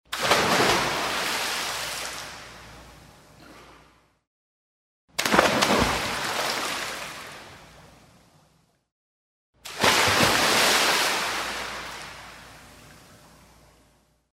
На этой странице собраны разнообразные звуки прыжков в воду: от легких всплесков до мощных ударов о поверхность.
7. Нырнули в бассейн поплавать